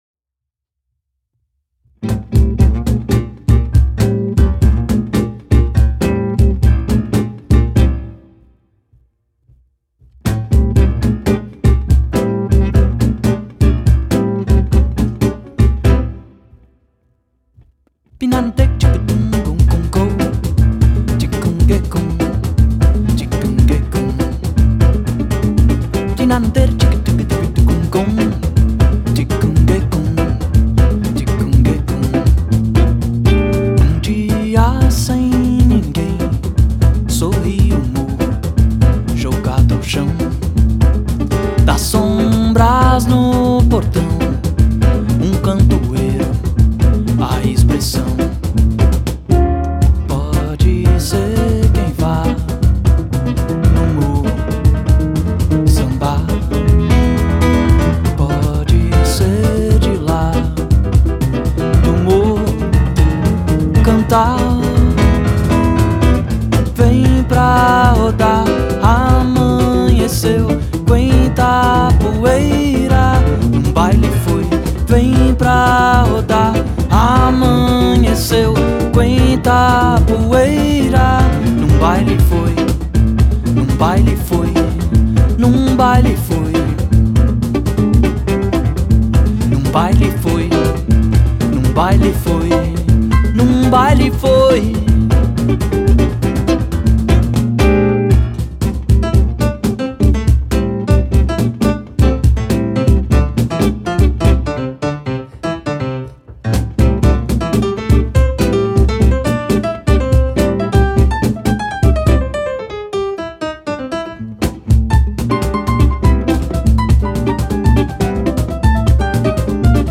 Brazilian guitarist, singer and composer
pianist, piano accordionist and composer
multi-percussionist